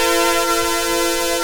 Index of /90_sSampleCDs/USB Soundscan vol.02 - Underground Hip Hop [AKAI] 1CD/Partition D/06-MISC
SYNTH     -L.wav